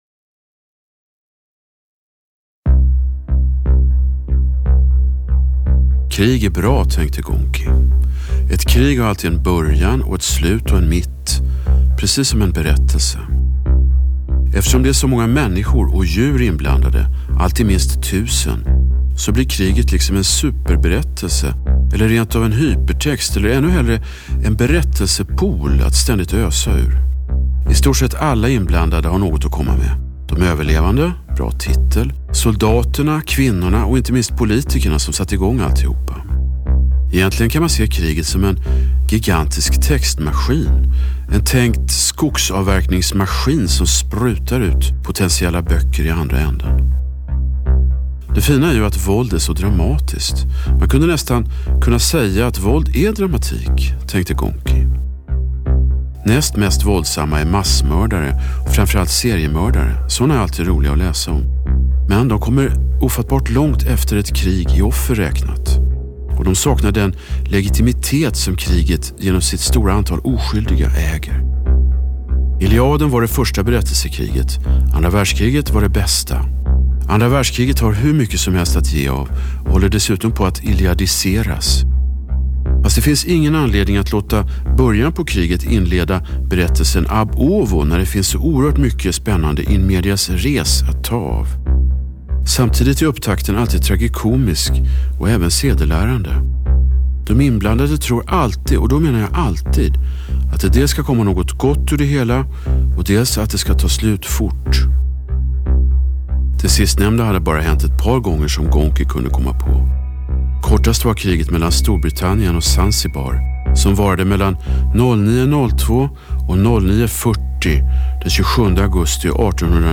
ett hörspel
Musikaliskt hörs uppenbara influenser från tysk kraut